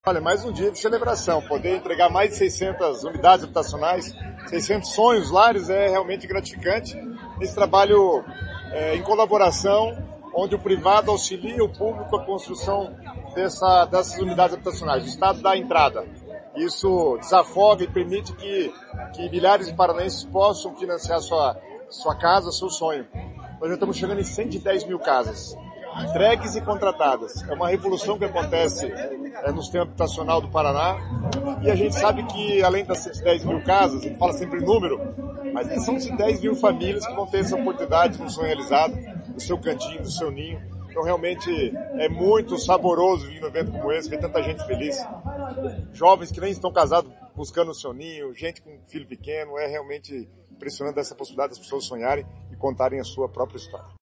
Sonora do secretário das Cidades, Guto Silva, sobre a inauguração de um condomínio com apoio do Estado em Almirante Tamandaré